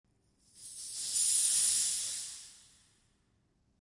Download Steam sound effect for free.
Steam